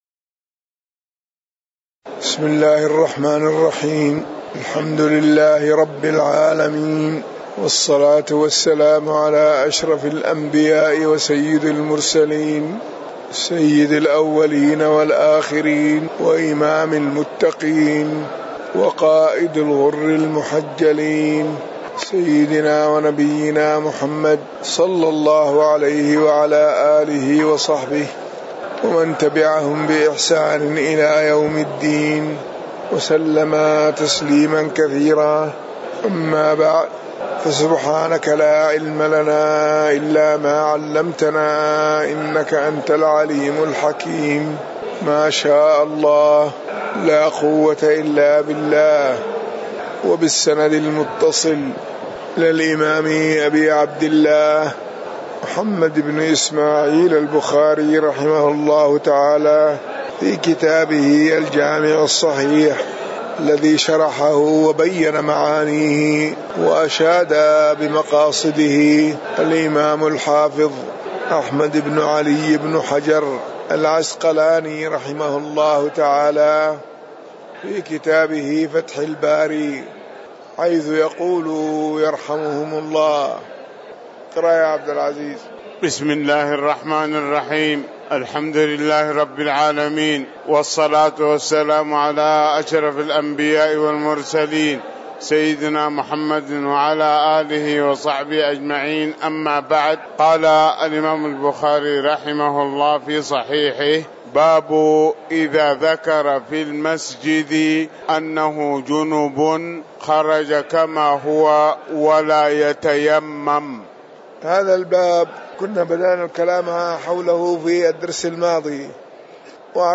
تاريخ النشر ٢ جمادى الأولى ١٤٤٠ هـ المكان: المسجد النبوي الشيخ